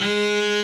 b_cello1_v100l2o4gp.ogg